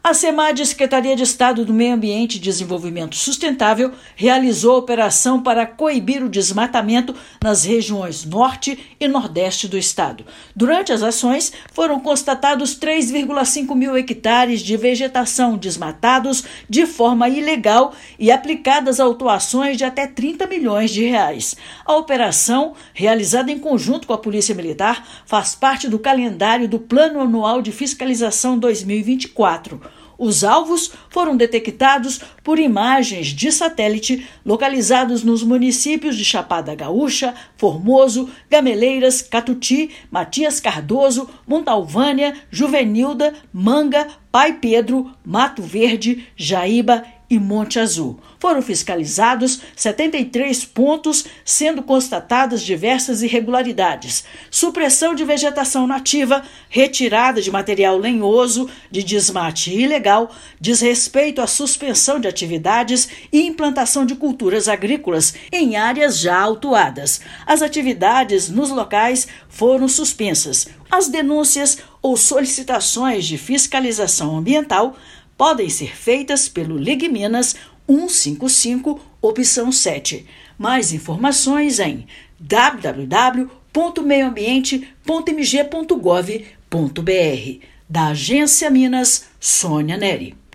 Alvos foram áreas de desmatamento detectadas por satélite em 12 municípios das regiões. Ouça matéria de rádio.